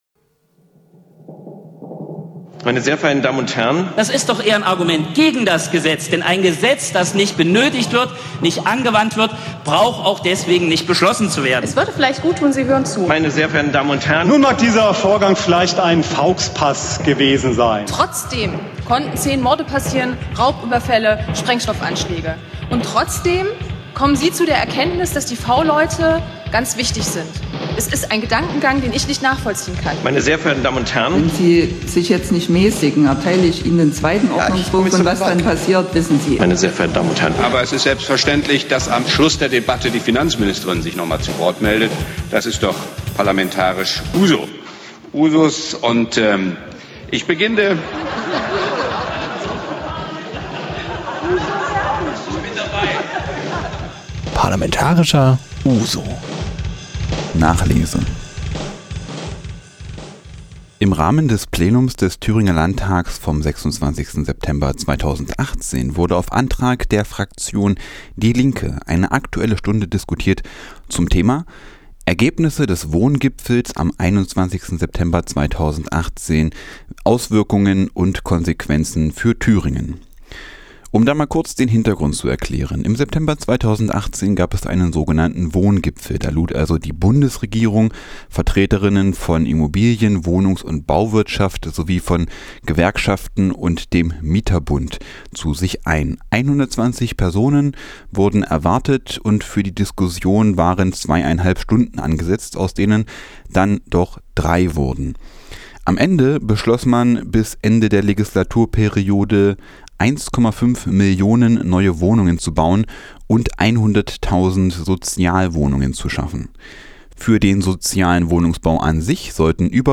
Berichte und Interviews vom Plenum des Thüringer Landtags (27.09.2018)
In der Sendereihe "Parlamentarischer Ouzo" berichten Thüringer Lokalsender regelmäßig aus einem vom Landtag zur Verfügung gestellten Senderaum in unmittelbarer Nähe zum Plenarsaal. Hier versammeln wir nochmal die Beiträge der Livesendung vom 27. September 2018, gestaltet durch Radio F.R.E.I. und Wartburg-Radio.